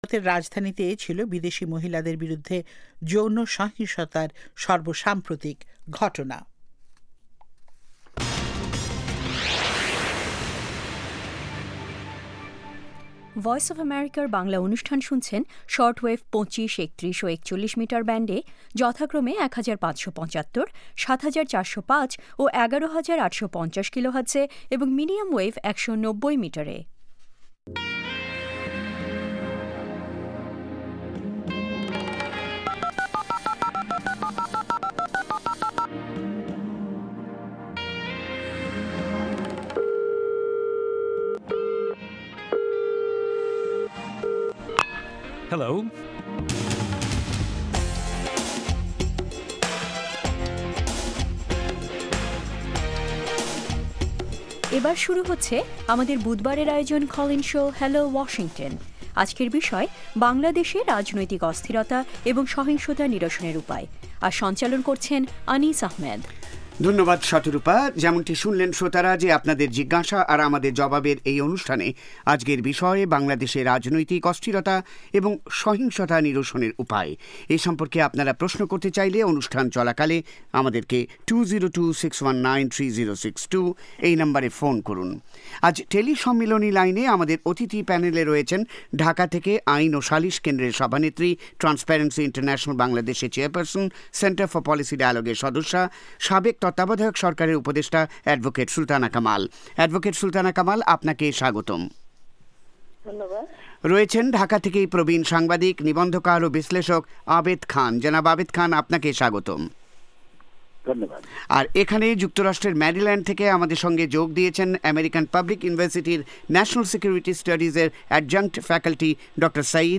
Hello Washington is VOA Bangla's flagship call-in show, providing in-depth analysis of major social and political issues in the United States and Bangladesh.